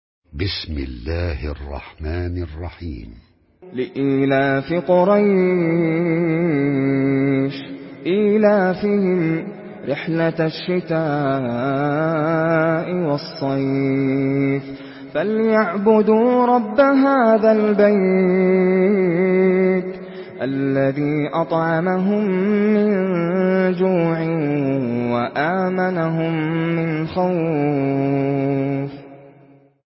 Surah Kureyş MP3 by Nasser Al Qatami in Hafs An Asim narration.
Murattal Hafs An Asim